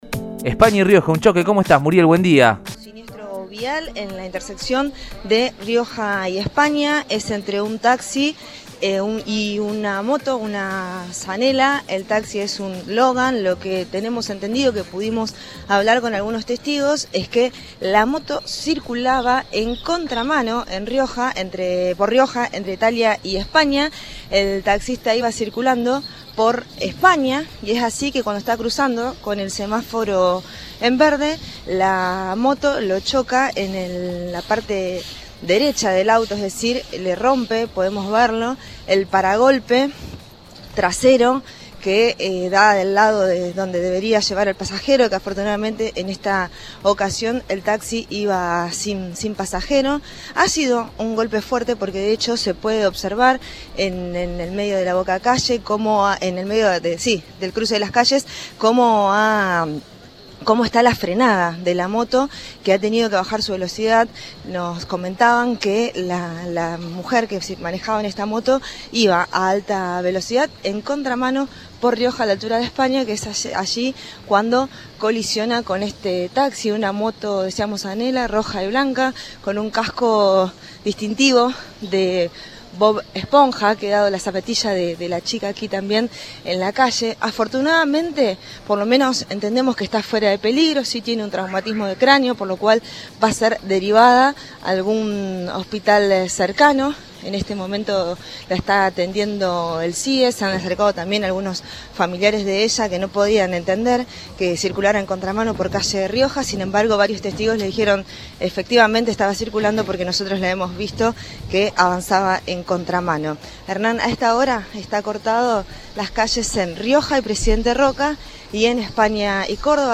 El móvil de Cadena 3 Rosario, en Radioinforme 3, precisó que el taxi –un Renault Logan- recibió el impacto de la moto cuando acababa de cruzar el semáforo.